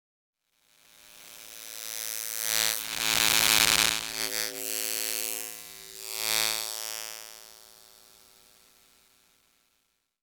ElectricityBuzz.R.wav